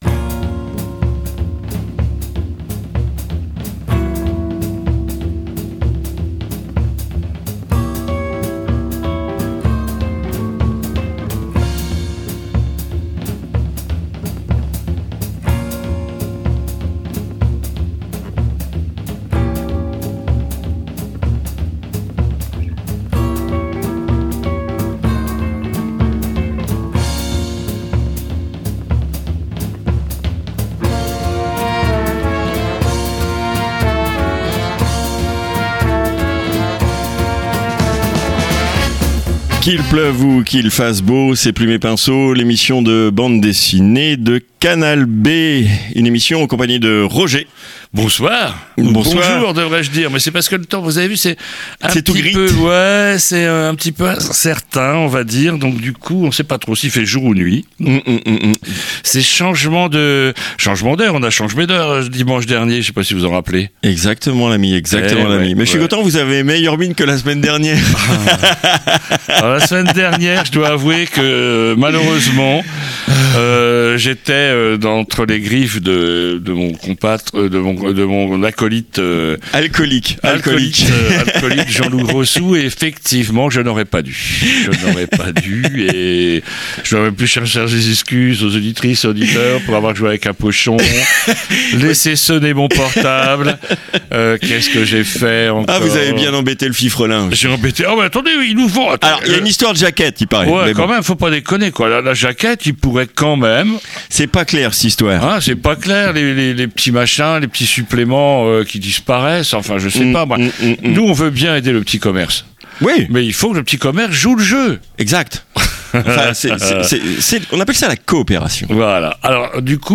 III - interview